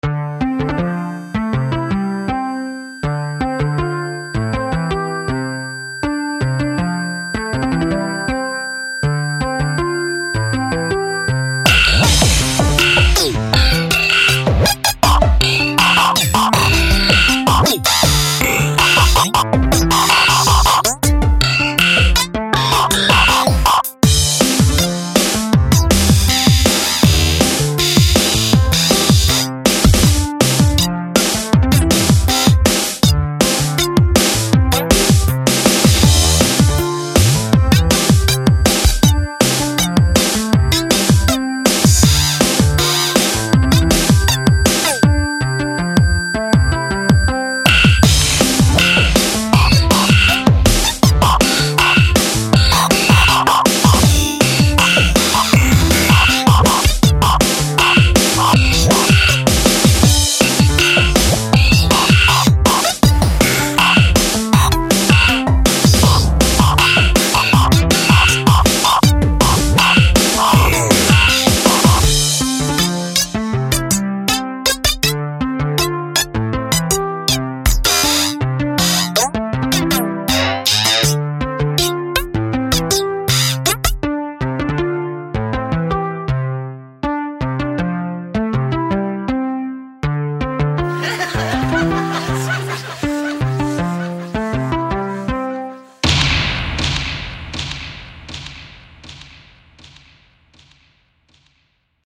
IDM/D'n'B